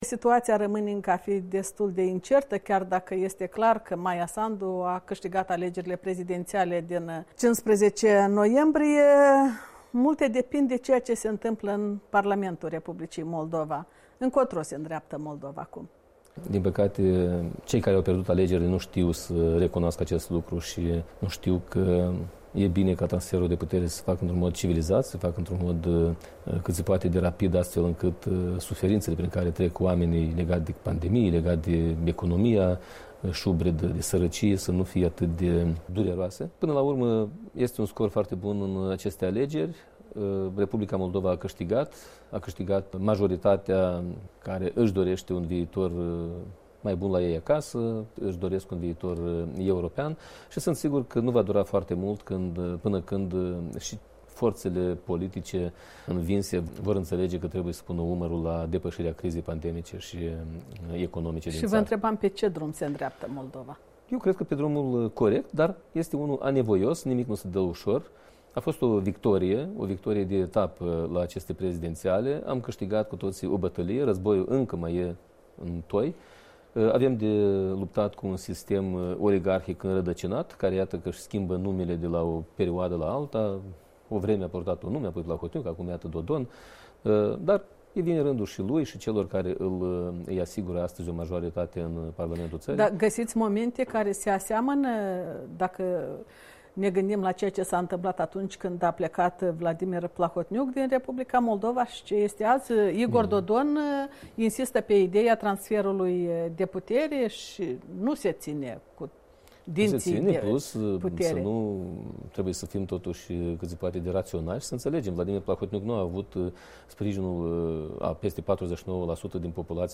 Interviu cu Andrei Năstase, liderul Platformei DA